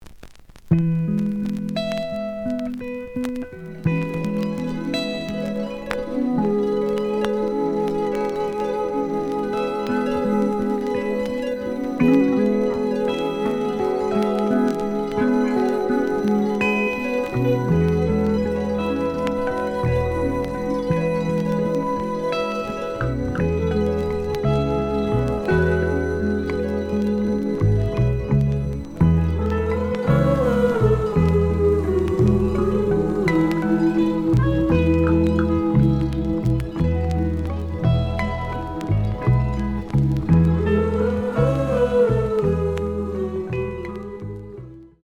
試聴は実際のレコードから録音しています。
The audio sample is recorded from the actual item.
●Genre: Soul, 70's Soul